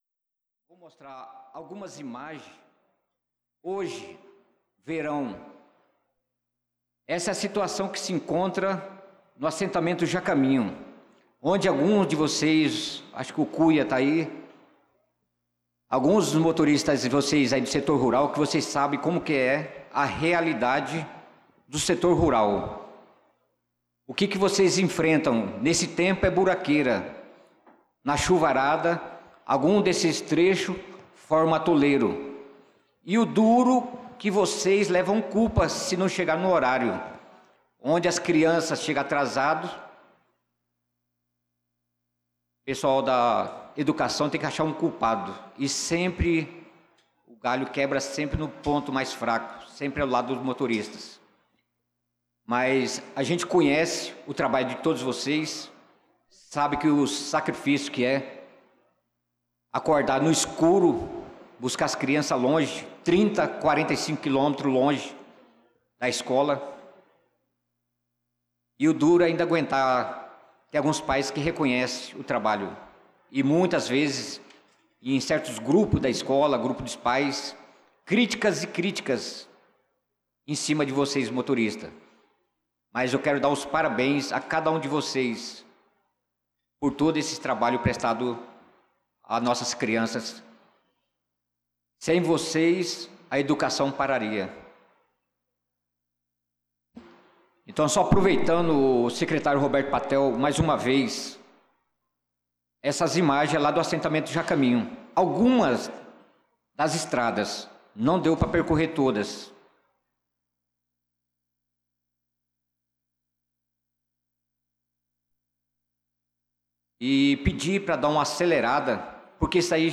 Pronunciamento do vereador Naldo da Pista na Sessão Ordinária do dia 23/06/2025.